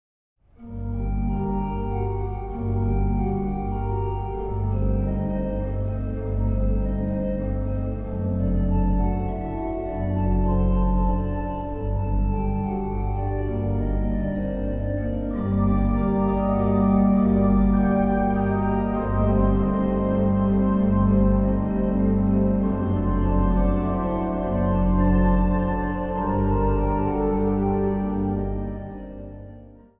het Steinmeijer-orgel van de Heiliggeistkirche te Heidelberg
Instrumentaal | Orgel